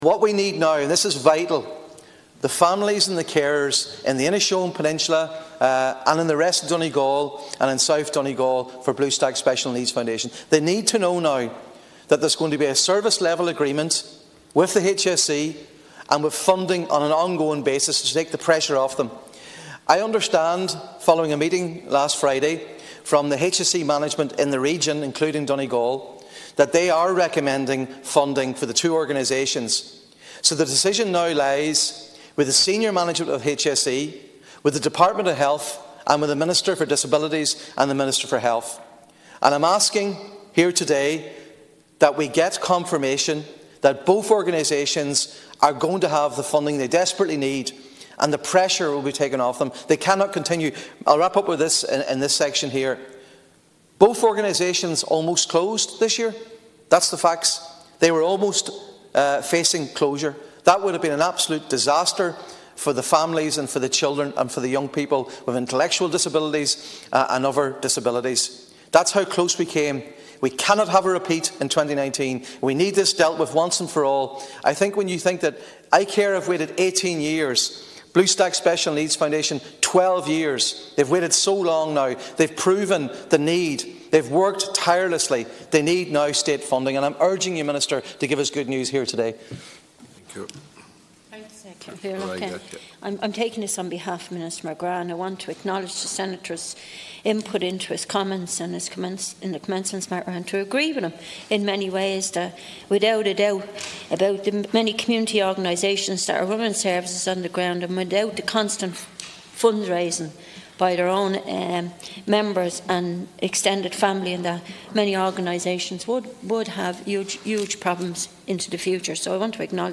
The Seanad has been told that there will be deep anger in Donegal if two organisations which provide services to children and young people with autism and intellectual disabilities are not properly funded.
Responding, Minister Catherine Byrne acknowledged Senator Mac Lochlainn’s passion, and said she will bring his concerns to Minister Finian Mc Grath.